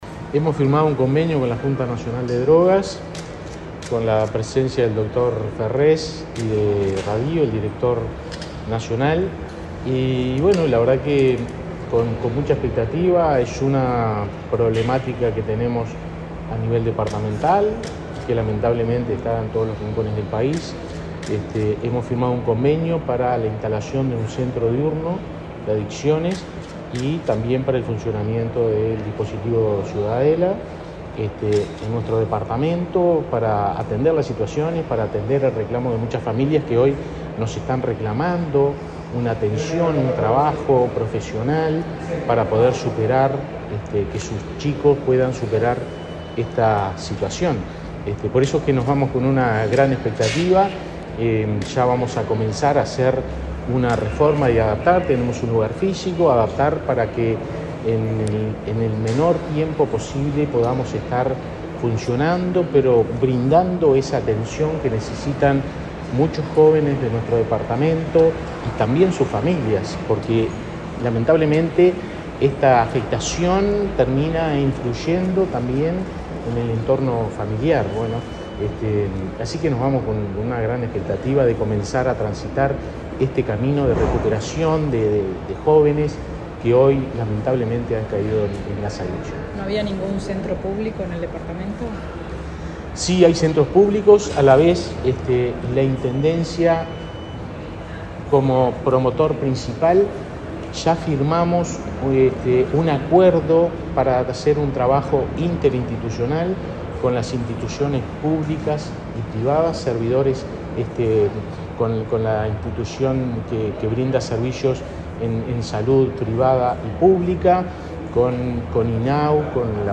Declaraciones del intendente de Flores, Fernando Echeverría
Declaraciones del intendente de Flores, Fernando Echeverría 20/04/2023 Compartir Facebook X Copiar enlace WhatsApp LinkedIn Con la presencia del prosecretario de la Presidencia, Rodrigo Ferrés, la Junta Nacional de Drogas y la Intendencia de Flores firmaron un acuerdo este jueves 20 en la Torre Ejecutiva, para fortalecer la Red Nacional de Drogas en ese departamento. El titular de la comuna, Fernando Echeverría, explicó a la prensa el alcance del convenio.